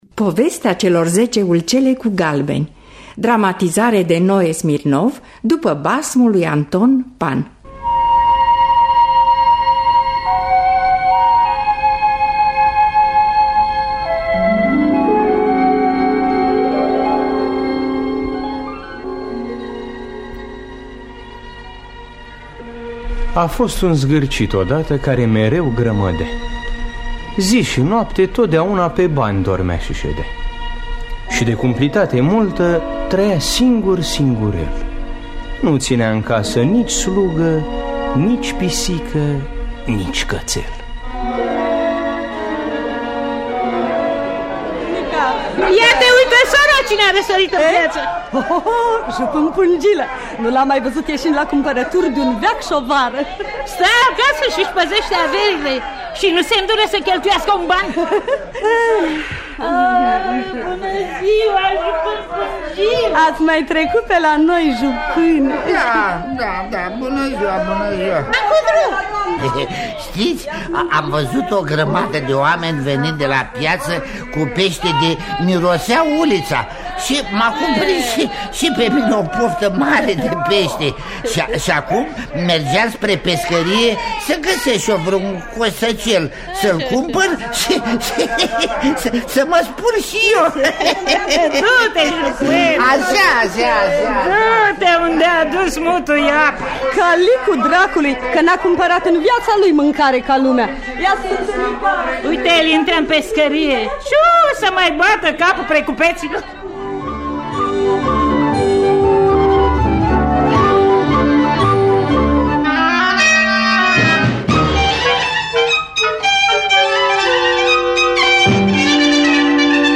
Înregistrare din anul 1966.